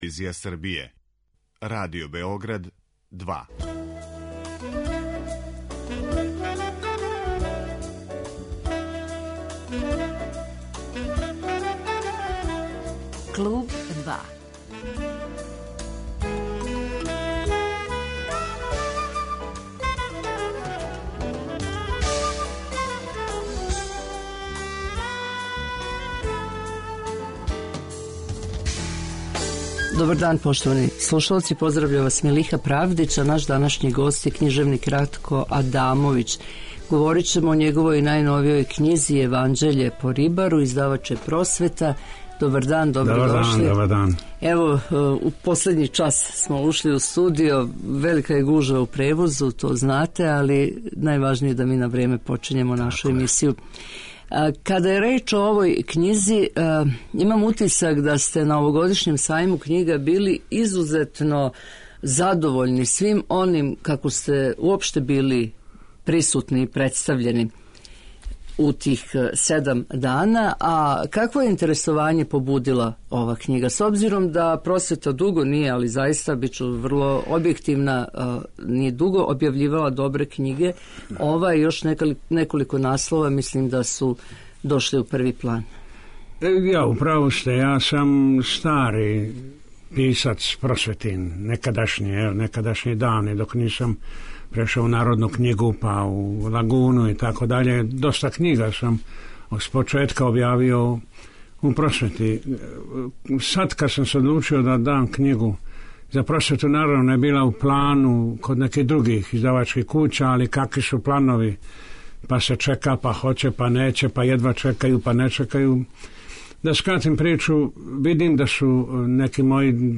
Гост Клуба 2 је књижевник